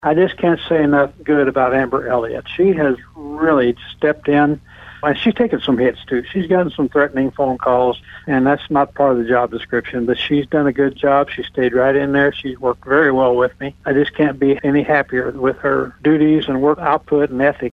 The Presiding Commissioner for St. Francois County, Harold Gallaher